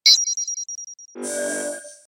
blimp_24895.mp3